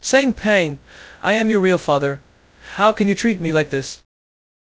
segment_2_voiceover.wav